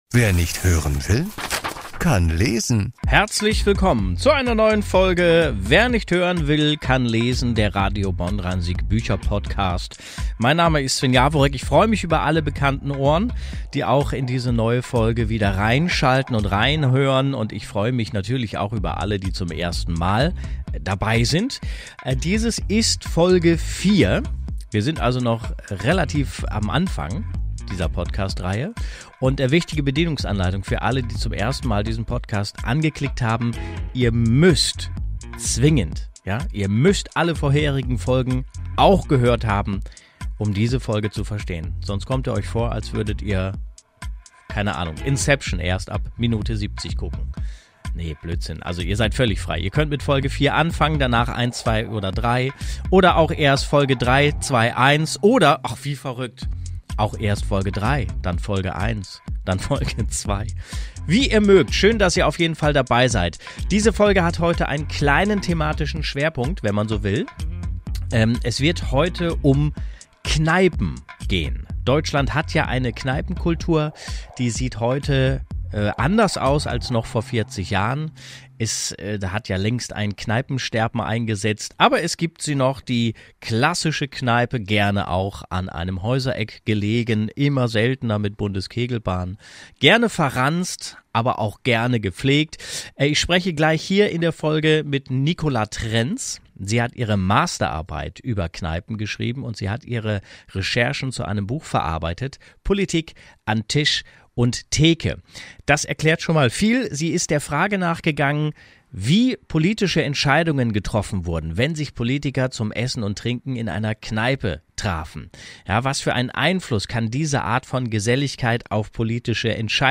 In dieser Folge gibt es u.a. ein Interview